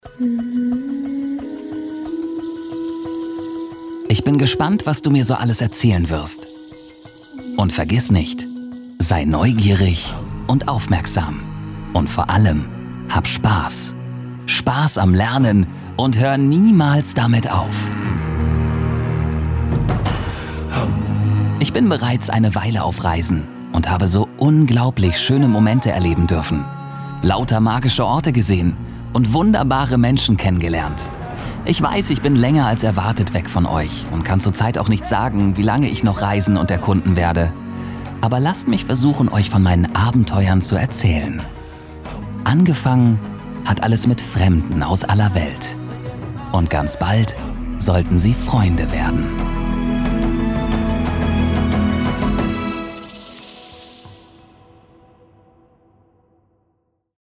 Meine Stimme: Frisch, lebendig, klar, präzise, freundlich, gefühlvoll, warm, verbindlich, seriös.
• Mikrofone: Neumann U87 Ai & TLM 103
Voiceover / Off-Stimme
DEMO-Erzaehler.mp3